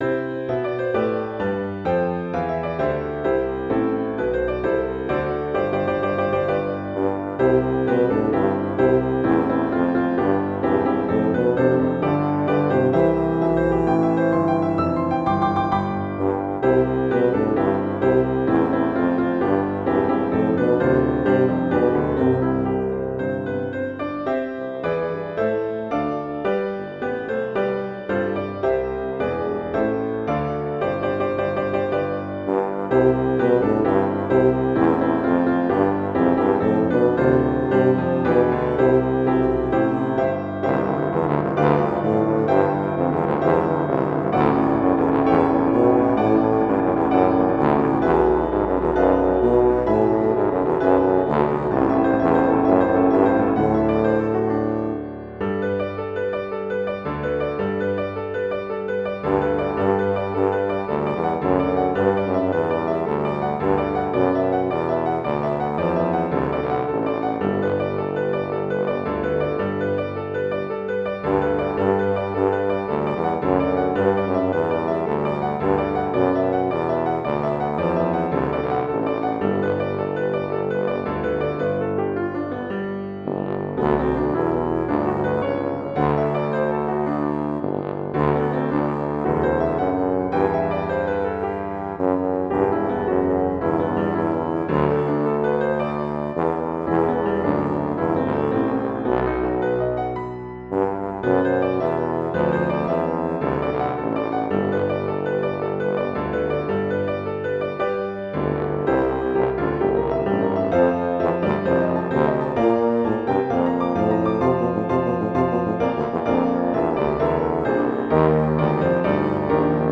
Intermediate Instrumental Solo with Piano Accompaniment.